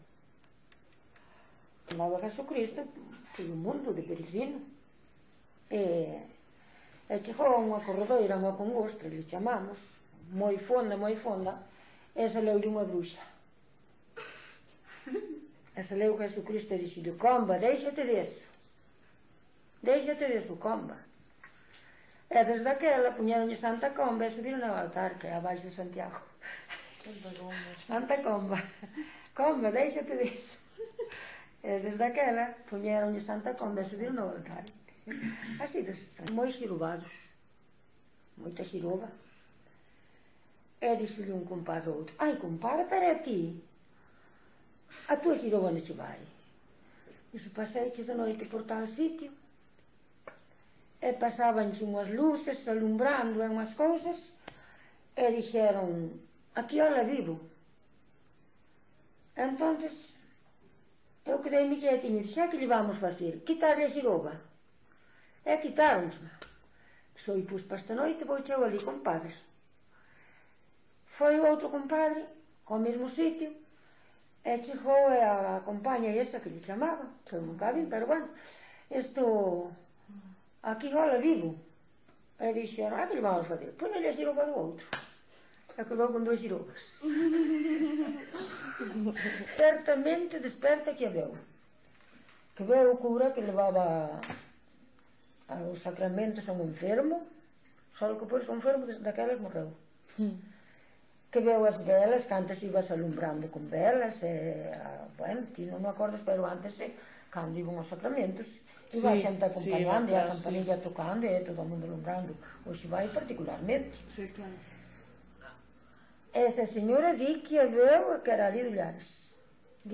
Contos e lendas.
Lugar de compilación: Vila de Cruces - Sabrexo (Santa María) - Sabrexo
Soporte orixinal: Casete
Instrumentos: Voz feminina